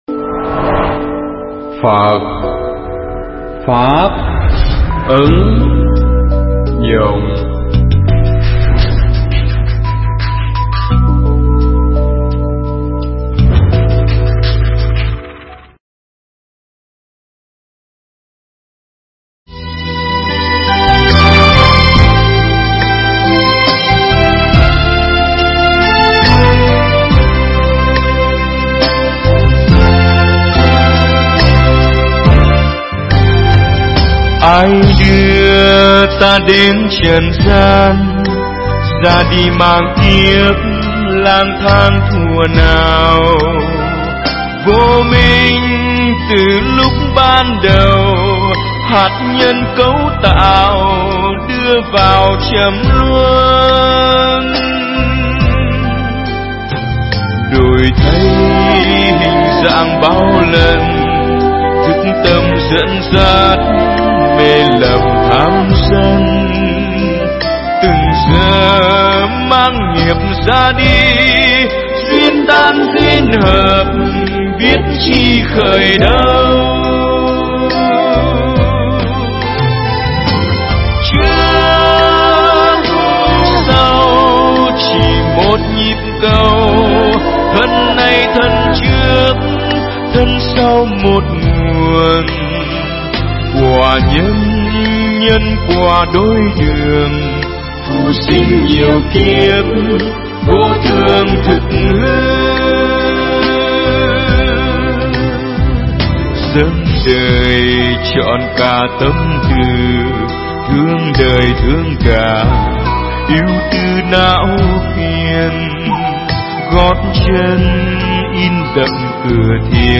Bài thuyết pháp